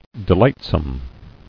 [de·light·some]